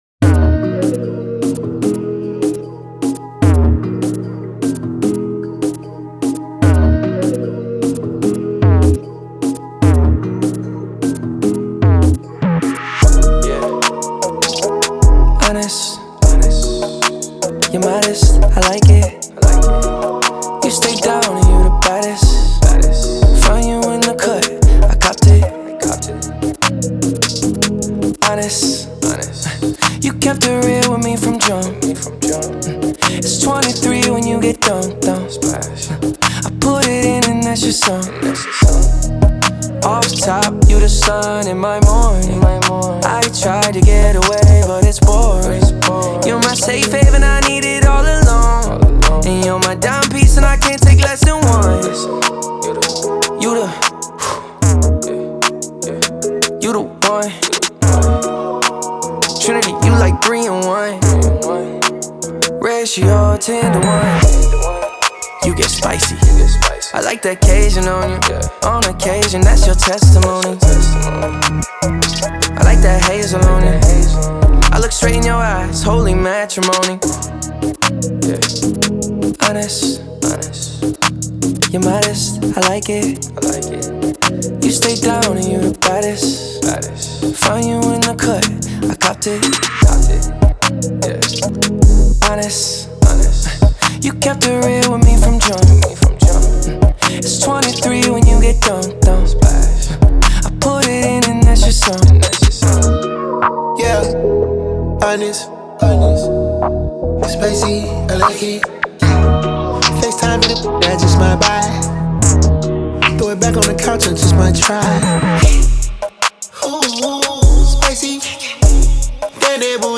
Popular gifted Canadian music singer and songwriter